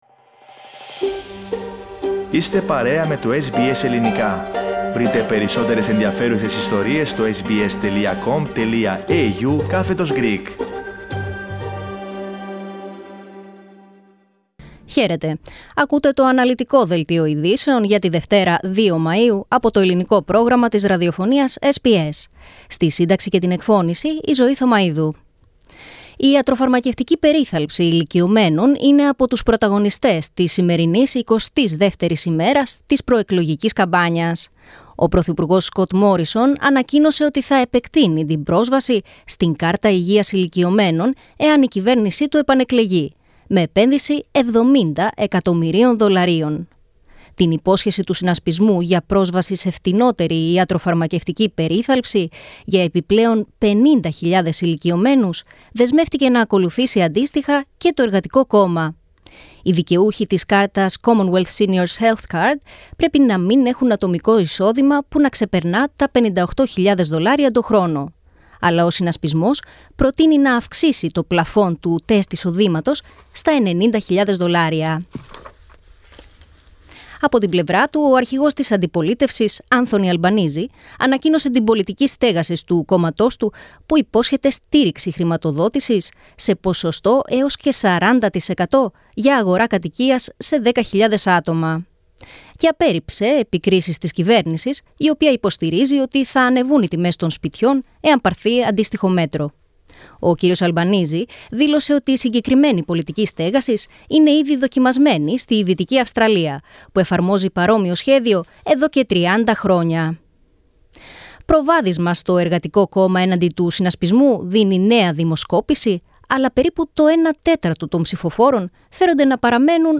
Δελτίο Ειδήσεων Δευτέρα 02.05.22